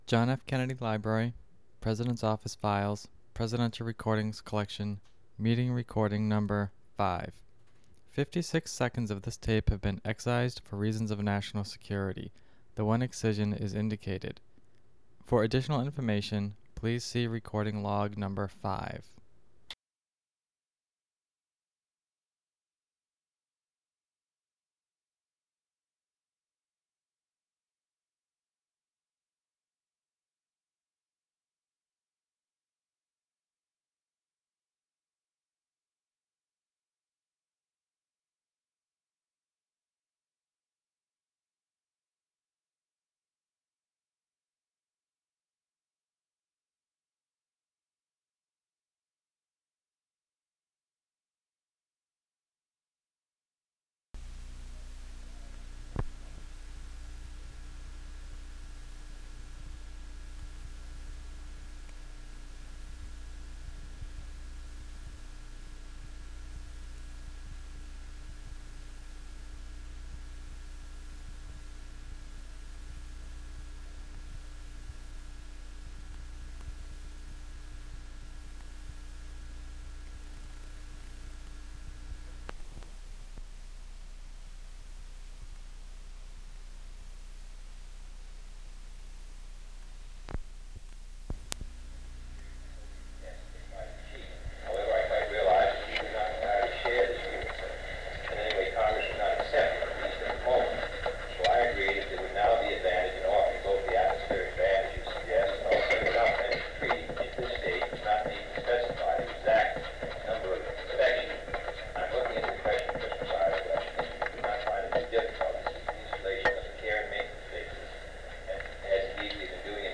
Secret White House Tapes | John F. Kennedy Presidency Meeting on Nuclear Test Ban Rewind 10 seconds Play/Pause Fast-forward 10 seconds 0:00 Download audio Previous Meetings: Tape 121/A57.